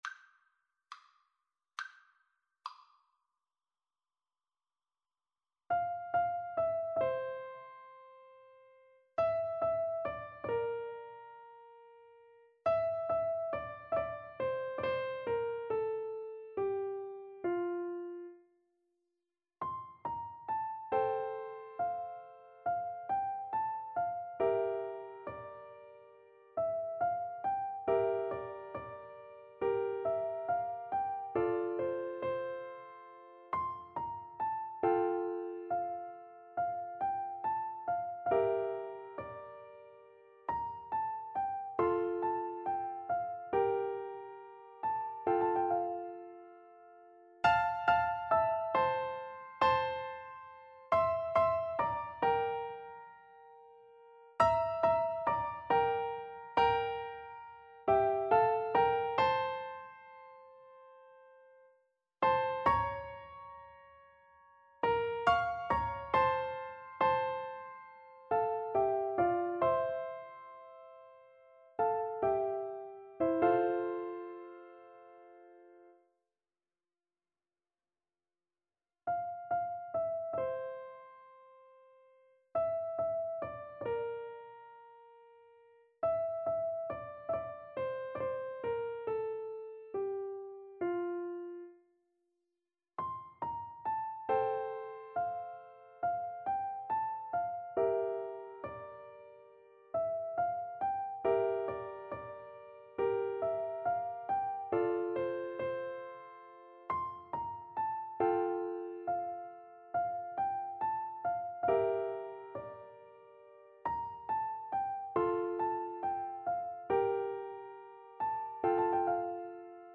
Piano Four Hands (Piano Duet)
2/4 (View more 2/4 Music)
Andantino = c. 69 (View more music marked Andantino)
Classical (View more Classical Piano Duet Music)